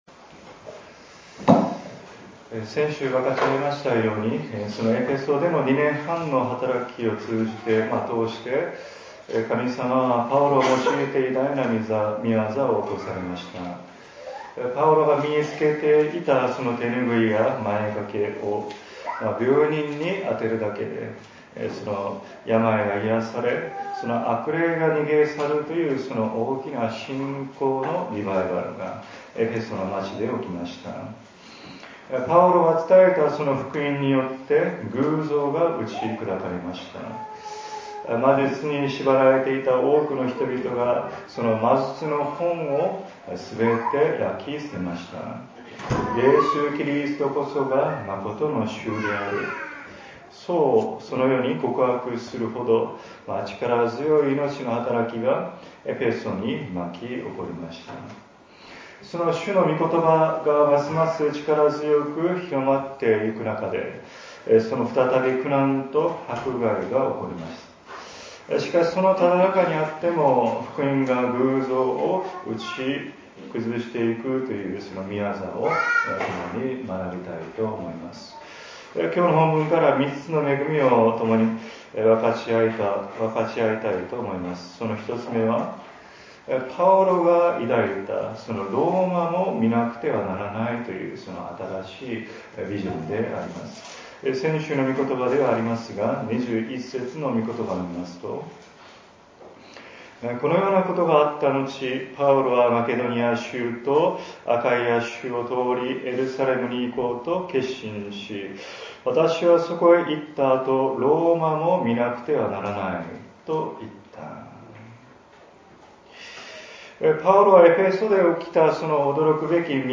礼拝次第